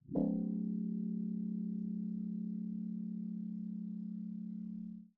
Fm-string.wav